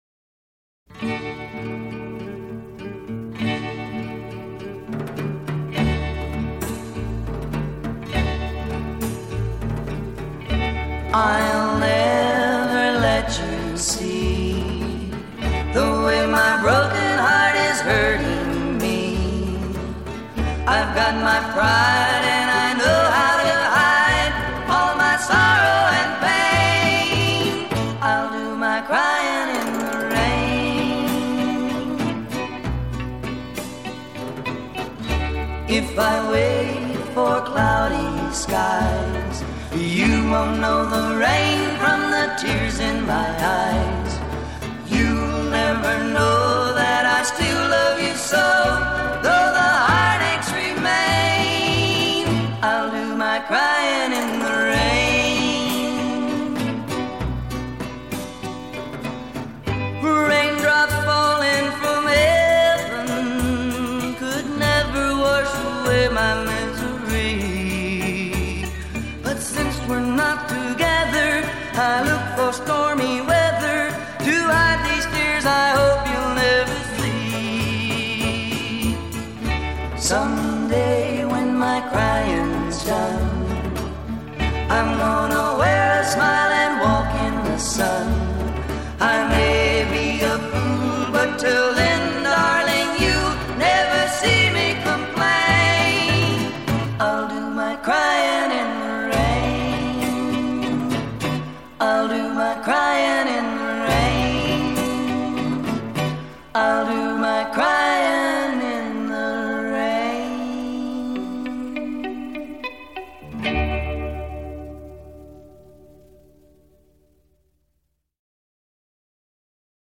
Жанр: Rockabilly